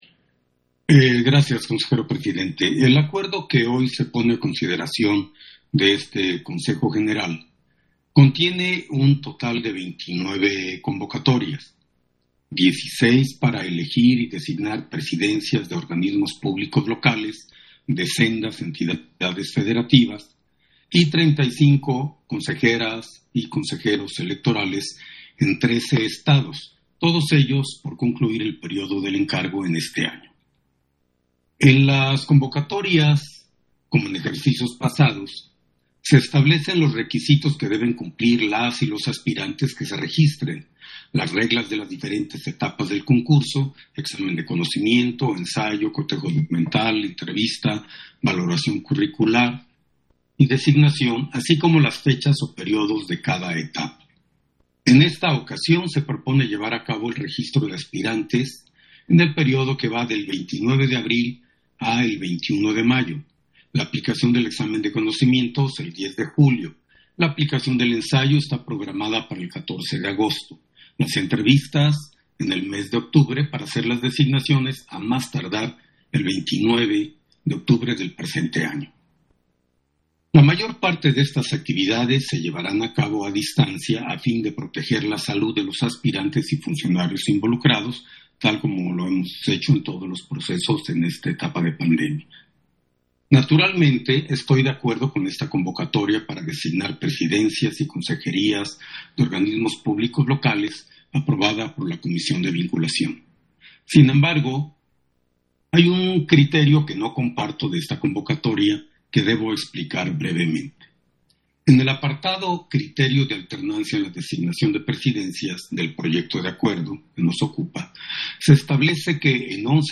Intervención de Jaime Rivera, en Sesión Ordinaria, en que se aprueban las convocatorias para la selección y designación de Consejeras y Consejeros Presidentes de OPL